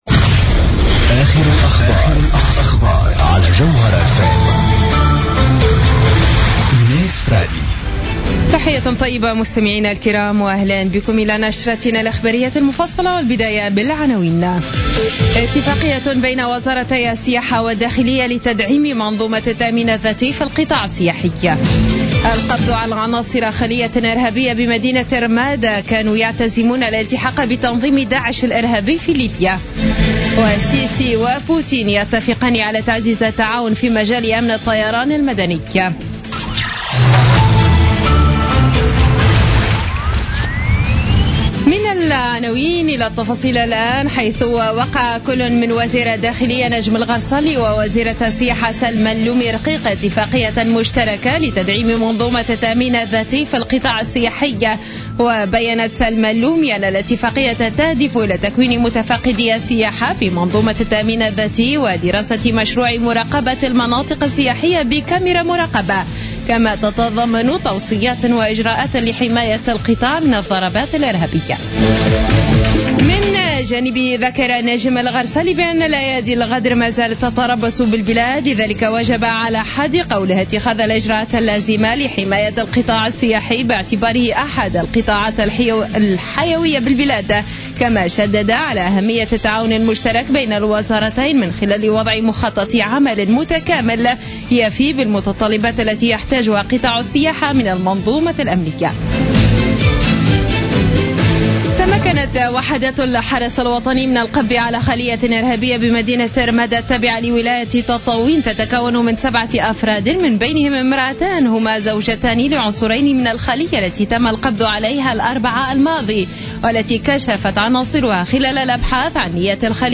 نشرة أخبار منتصف الليل ليوم السبت 07 نوفمبر 2015